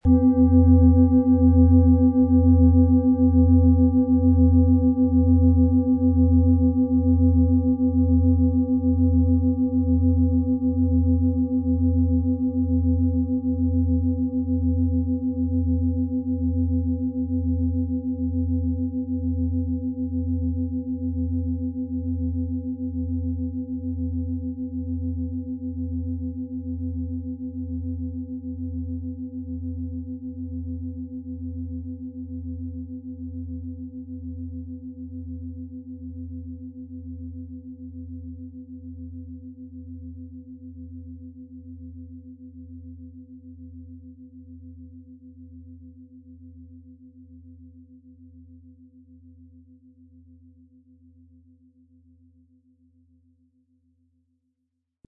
Antike Planetenschale® Sonne – Strahlkraft, Lebensfreude und inneres Licht
Sie entfaltet einen klaren Klang, der das Herz öffnet und das Gemüt aufhellt. Ihre feinen, warmen Vibrationen durchströmen den Körper, wecken das innere Licht und laden dich ein, dich mit deinem ureigenen Lebensfunken zu verbinden.
• Sanfter, heller Klang: Weckt Freude, stärkt Selbstvertrauen
Um den Original-Klang genau dieser Schale zu hören, lassen Sie bitte den hinterlegten Sound abspielen.
PlanetentonSonne & Delfin (Höchster Ton)
MaterialBronze